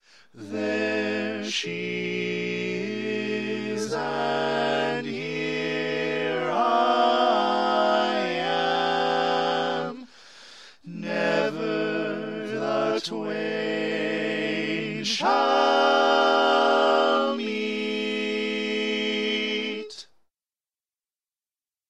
Key written in: A Minor
Type: Barbershop